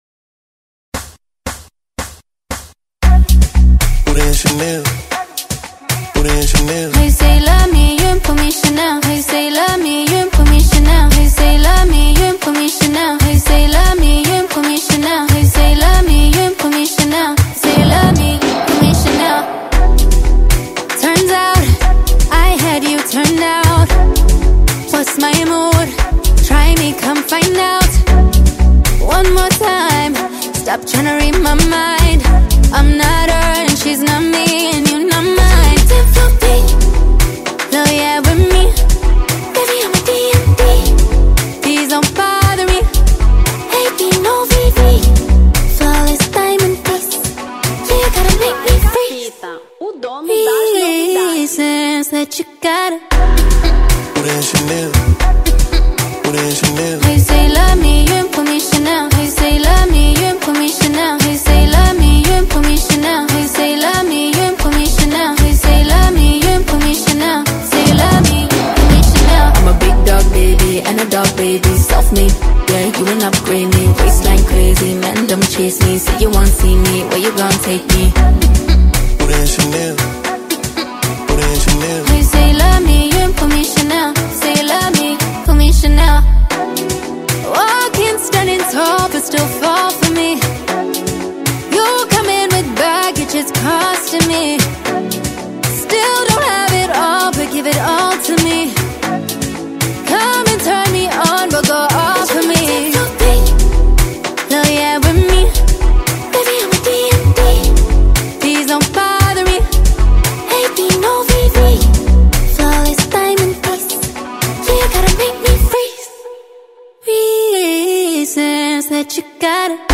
Amapiano 2025